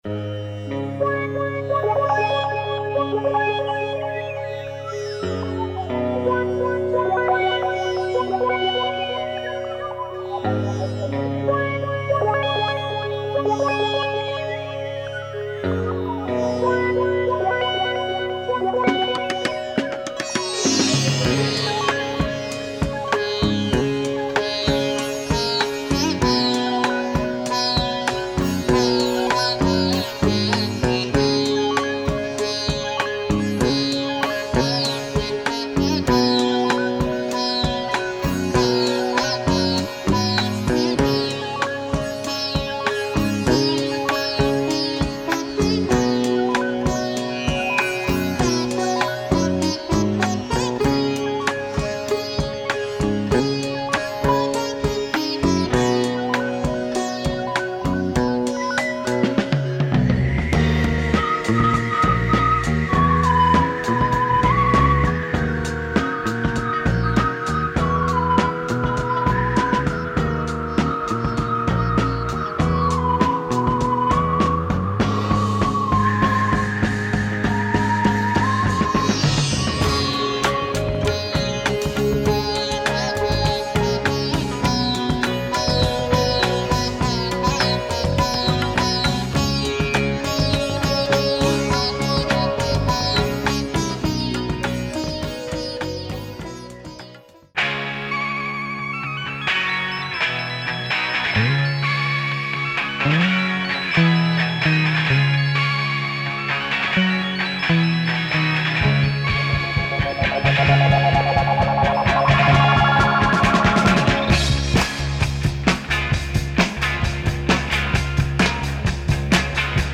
cult sitar player
Sitar banger !